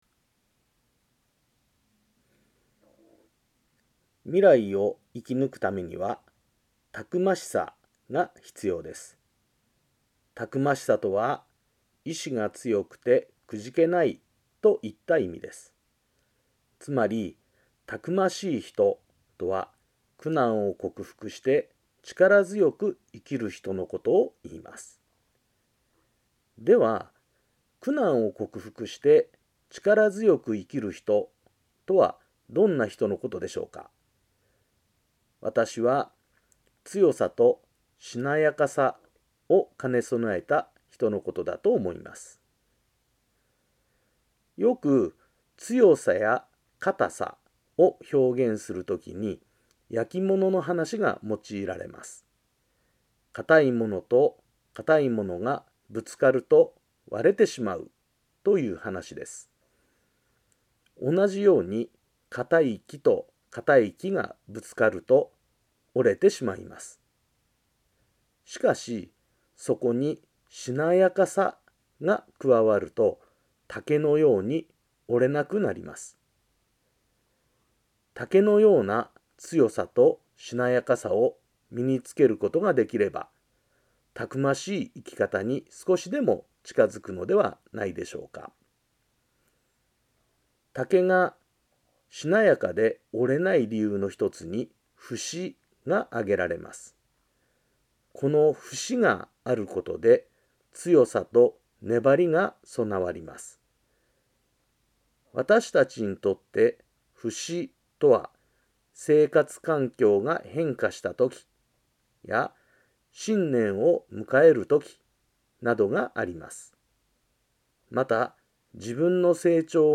曹洞宗岐阜県宗務所 > テレフォン法話 > 「未来をたくましく生きぬくために」